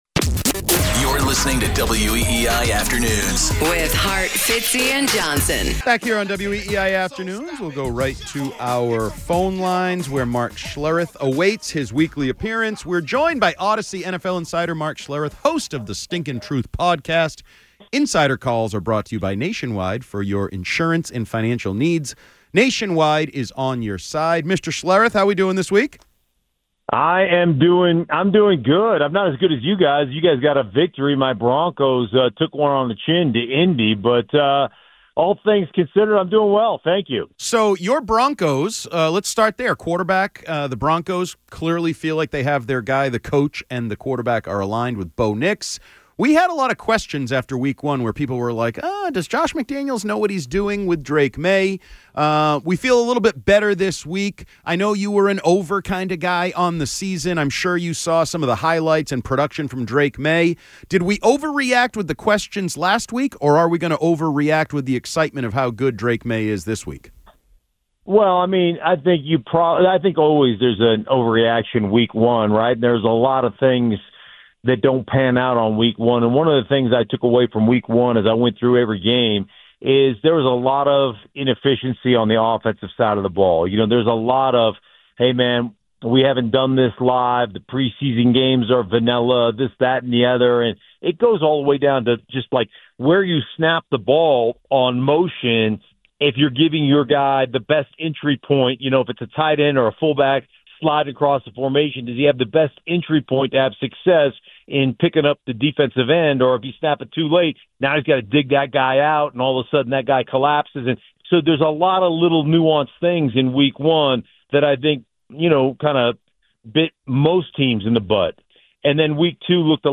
Mark Schlereth joins WEEI Afternoons - People overreacted to Drake Maye's play in week 1